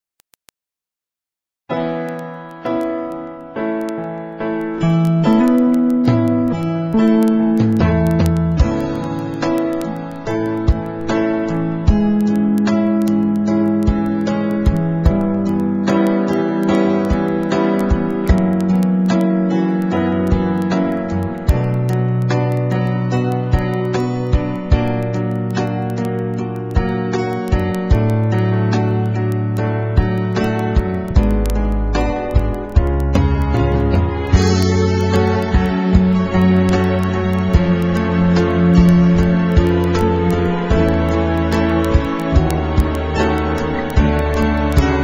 NOTE: Background Tracks 11 Thru 20